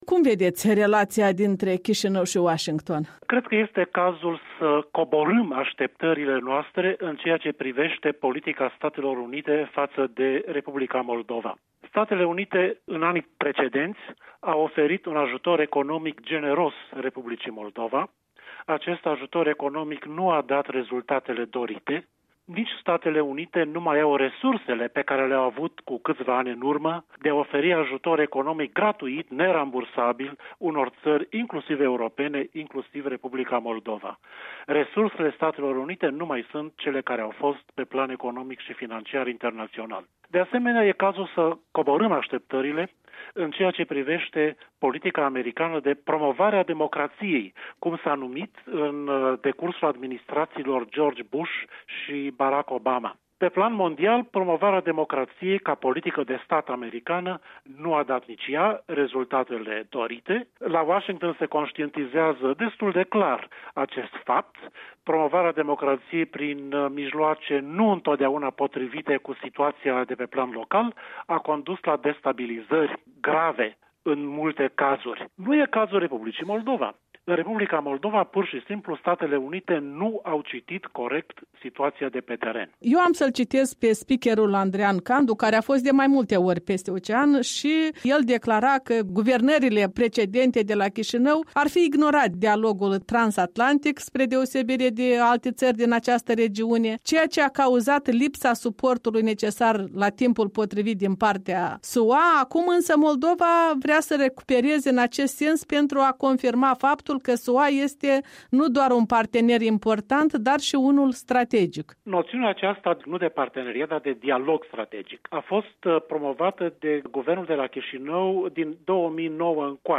Un interviu despre stadiul relațiilor americano-moldovene cu ocazia zilei de 4 iulie, Ziua Independenței Statelor Unite.